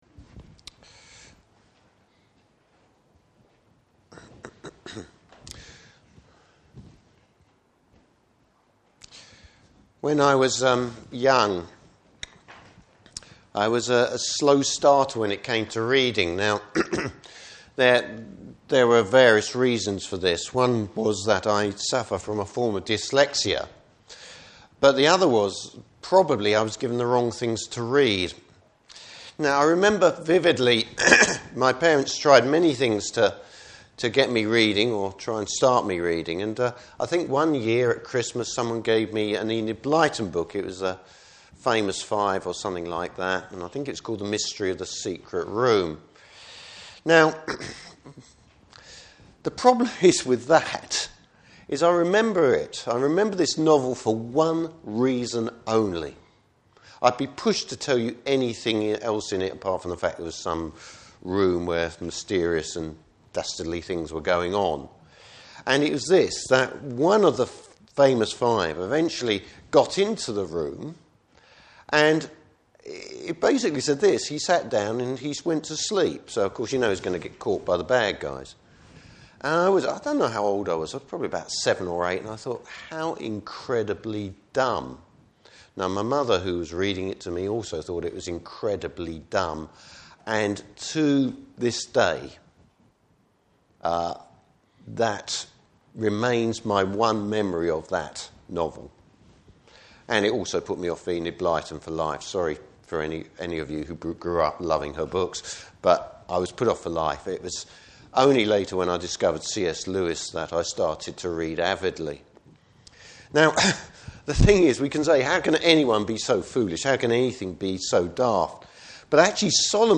Service Type: Morning Service Bible Text: Ecclesiastes 10:1-11:6.